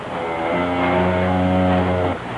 Long Moo Sound Effect
Download a high-quality long moo sound effect.
long-moo.mp3